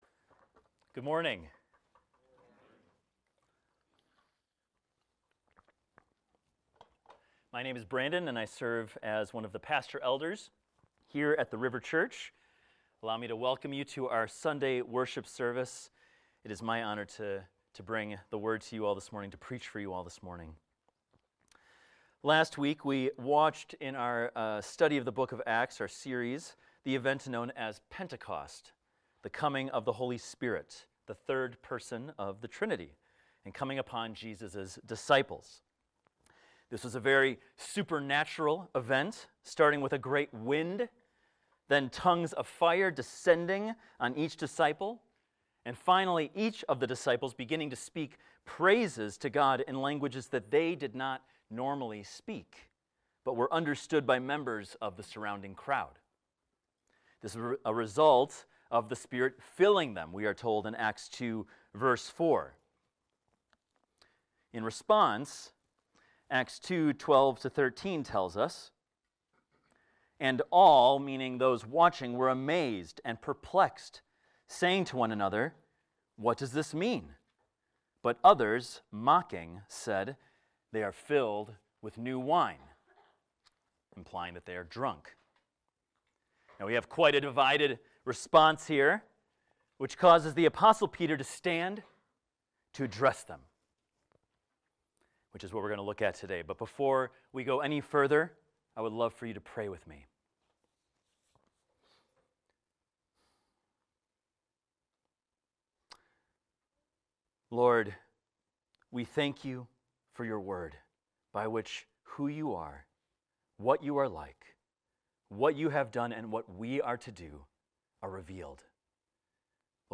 This is a recording of a sermon titled, "Spirit-Filled Preaching."